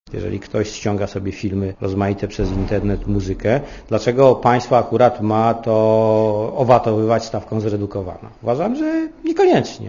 Mówi minister Raczko (40 KB)